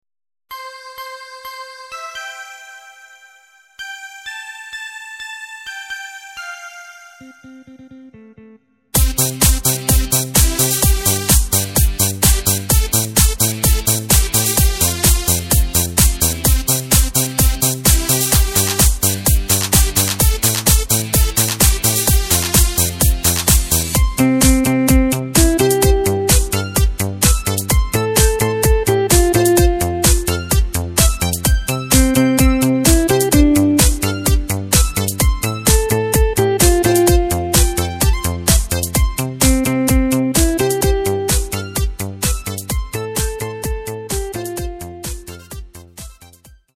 Takt:          4/4
Tempo:         128.00
Tonart:            C
Discofox (Austropop) aus dem Jahr 2016!